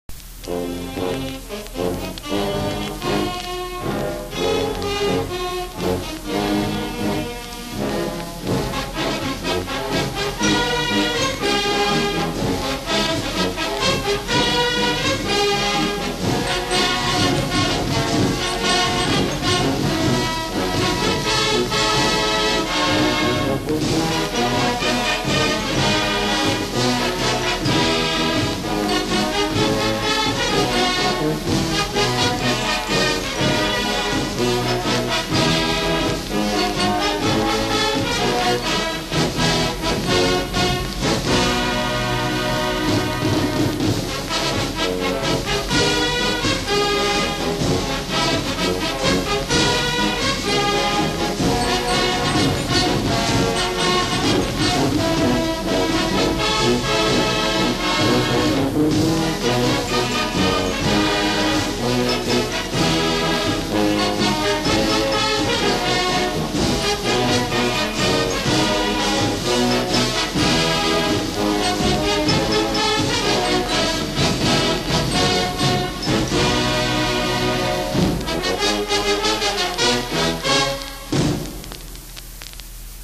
MCC Marching Band